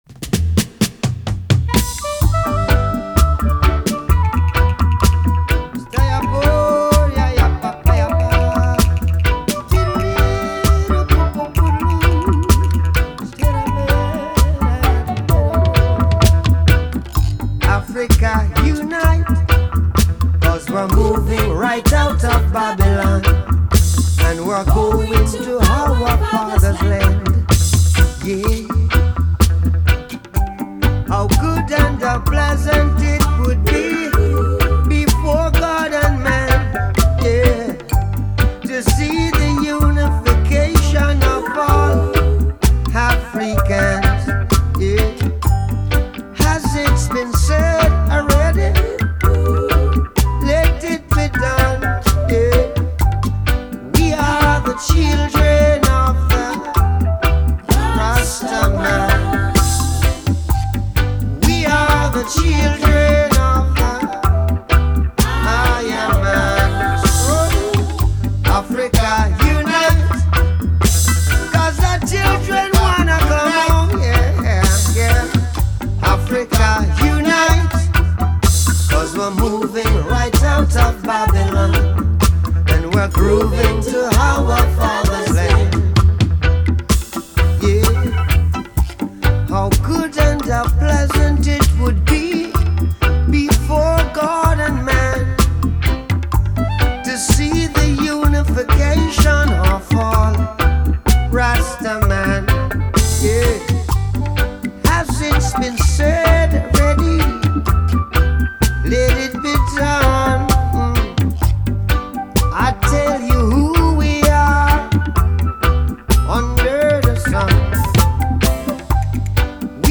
Регги